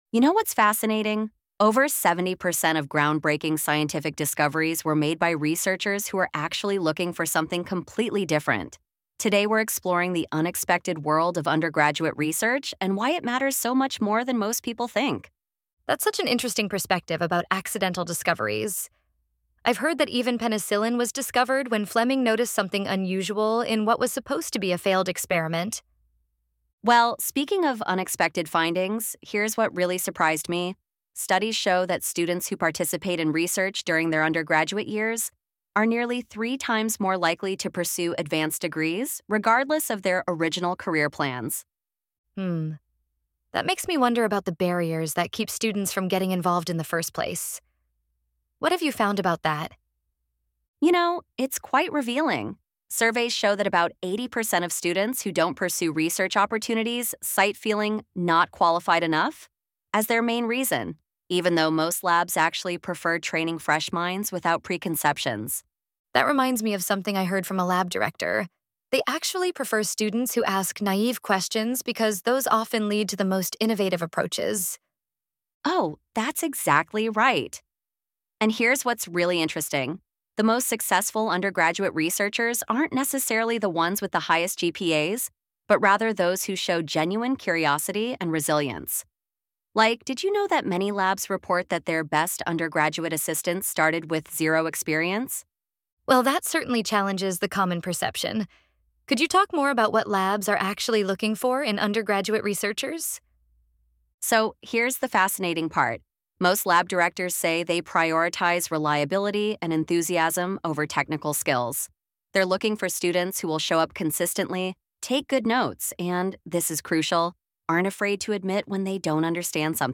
ElevenLabs_Unlocking_the_Hidden_Benefits_of_Undergraduate_Research.mp3